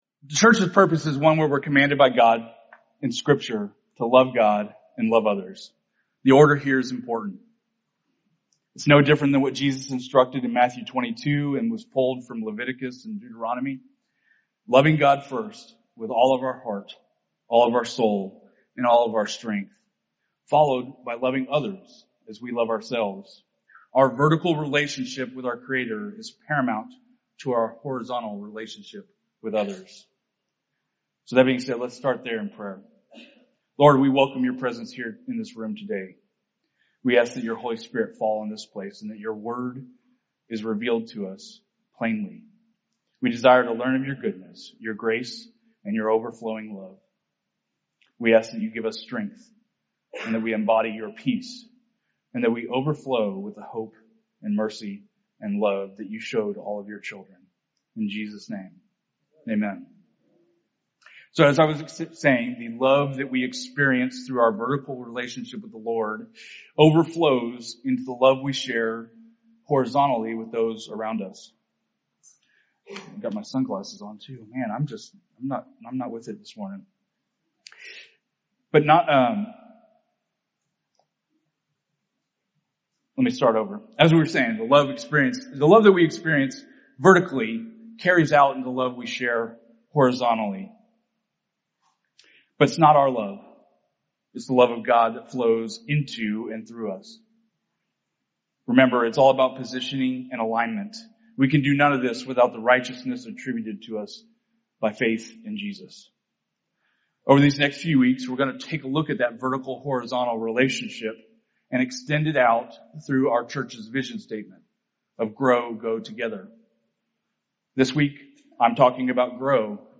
This sermon, invites us to deepen our relationship with God, allowing Him to prune and shape us for greater spiritual maturity.